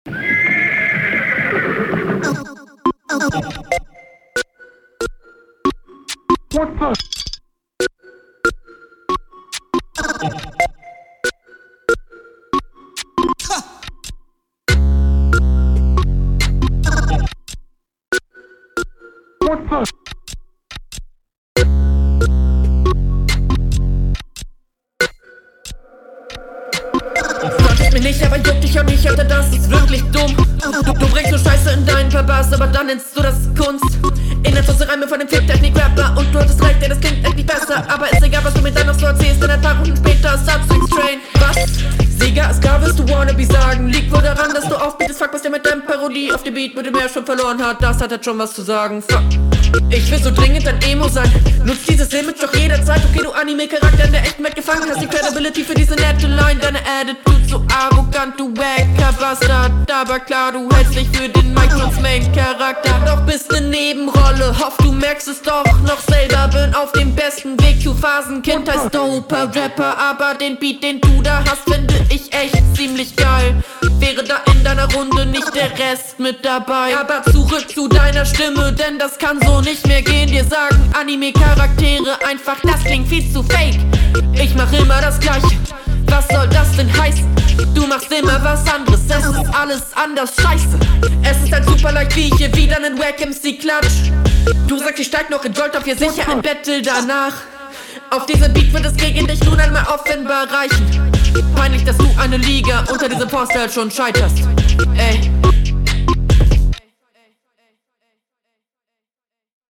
Manchmal krass geflowt und manchmal anders offbeat dazu gibts nicht viel zu sagen ausser "Steigerung"